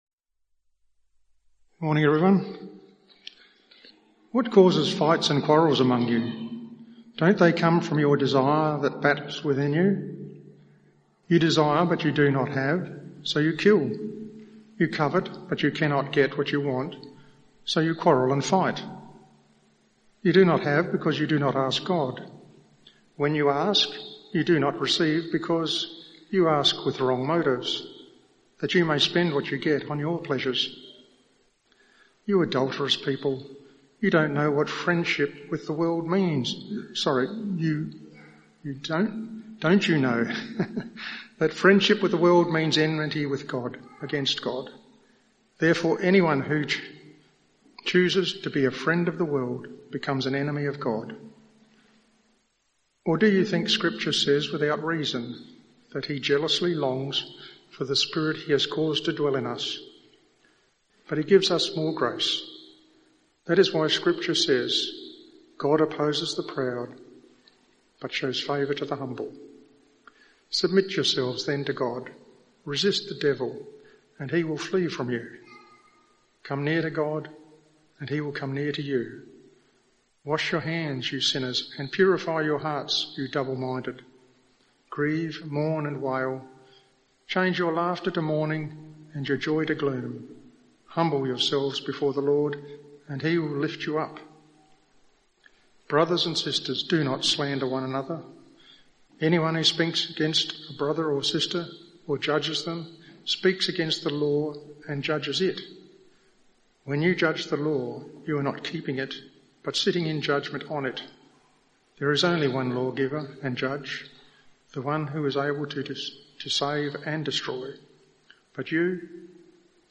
This talk from James 4:1–12 explores the inner conflict of human desires and the call to submit to God’s gracious rule.